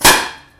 Knall1 lang
描述：Big firecracker explodes in a backyard surrounded by buildings.Silvester 2016/17, HamburgRecorded w/ Zoom H1
标签： cracker outdoor bang firecracker
声道立体声